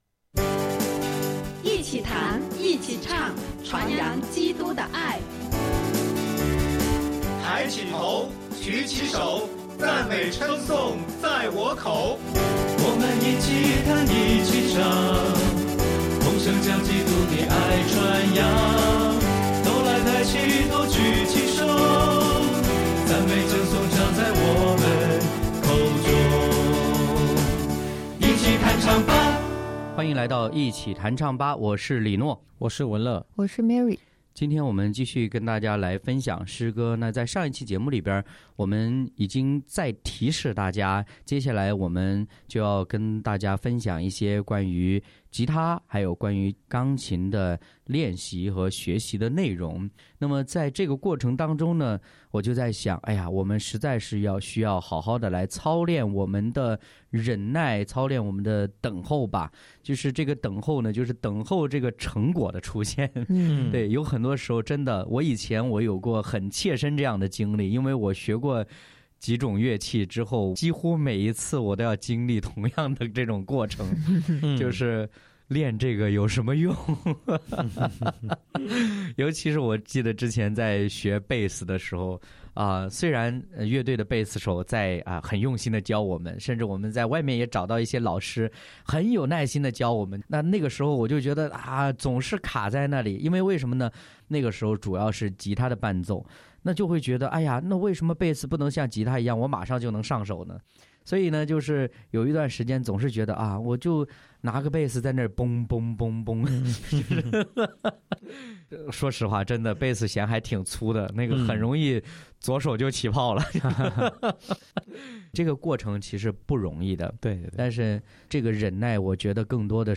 敬拜分享：忍耐不是忍受，而是有盼望的等候；诗歌：《等候神》、《A do nai我等候你》